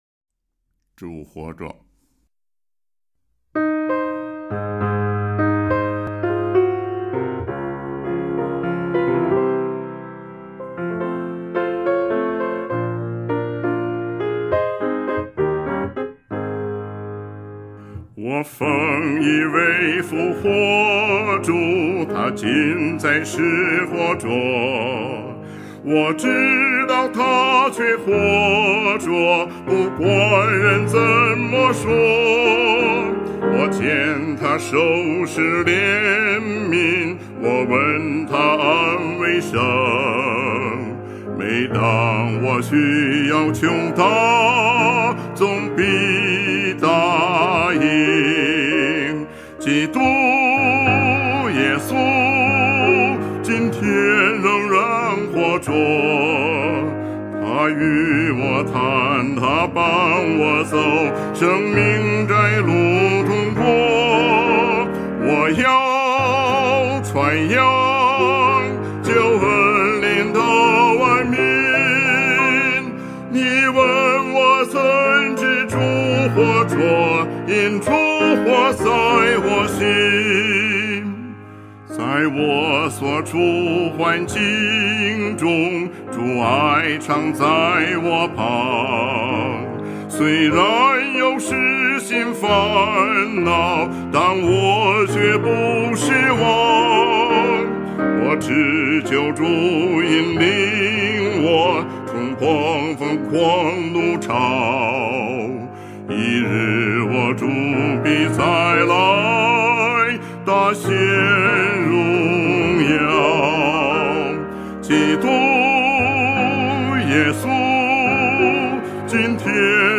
赞美诗《主活着》